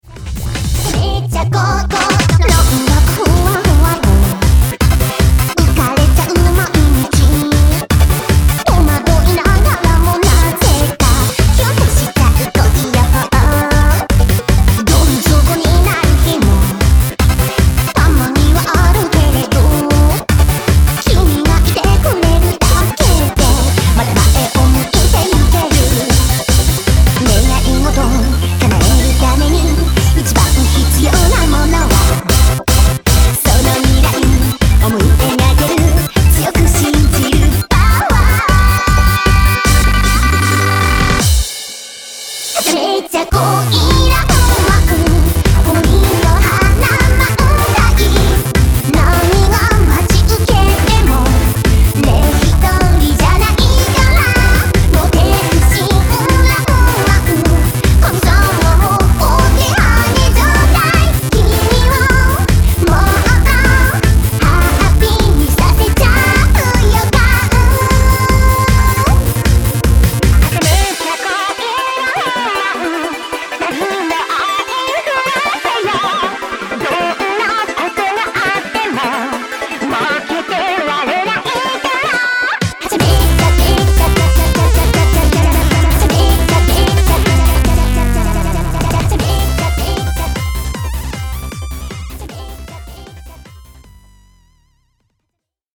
○ジャンル美少女ゲーム音楽・リミックスCD